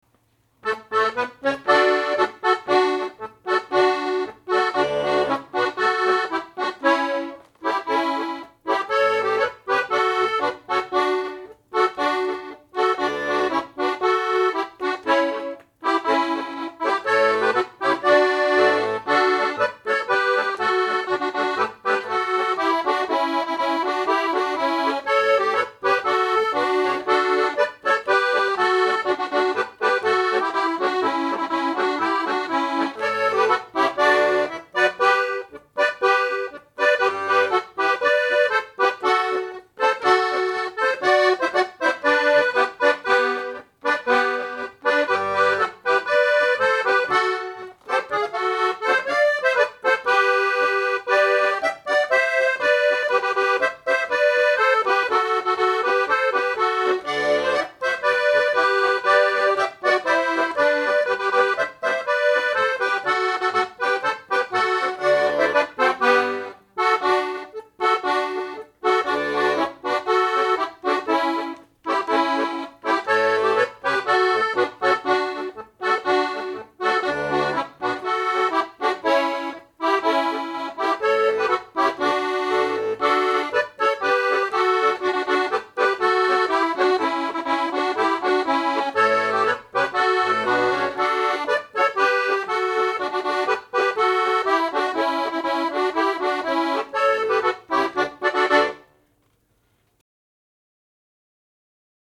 Točak - danse (mp3)